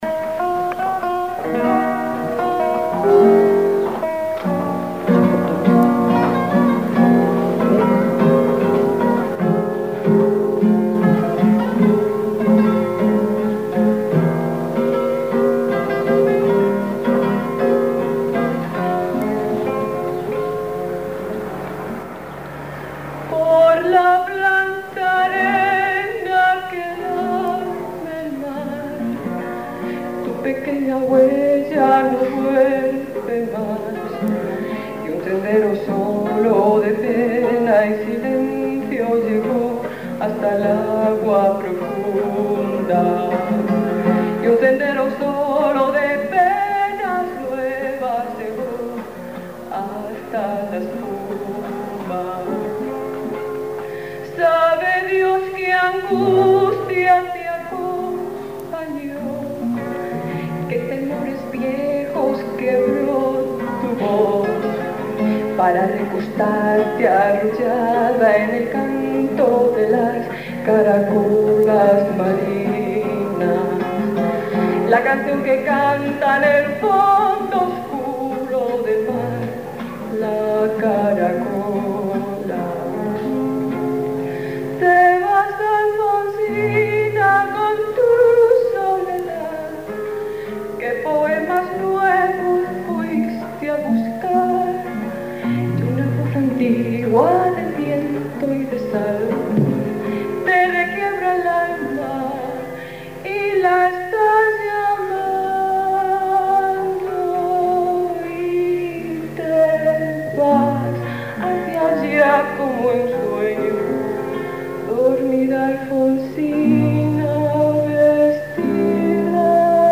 realizado en el Salón de Actos del Seminario de Toro (Zamora)
en directo, con un Cassete Philips de petaca.
VOZ
GUITARRAS
PERCUSIÓN